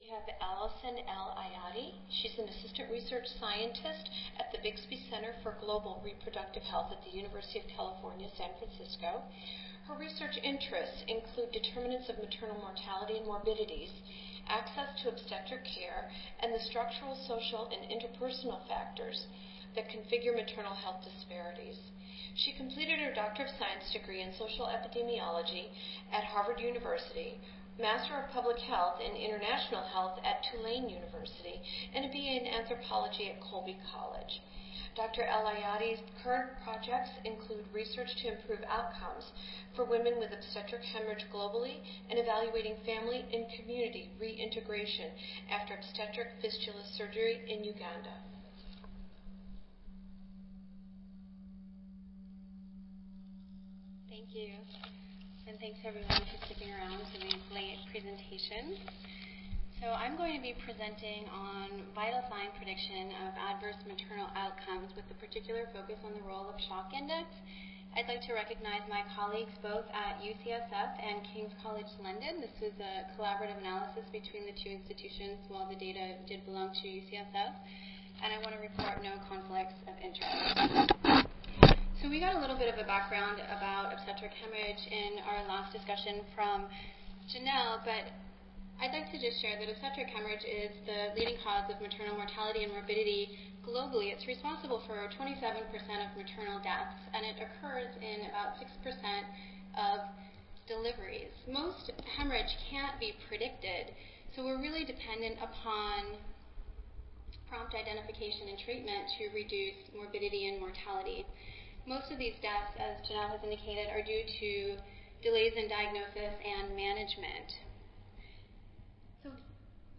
142nd APHA Annual Meeting and Exposition (November 15 - November 19, 2014): Early Clinical Predictors for Adverse Maternal Outcome from Obstetric Hemorrhage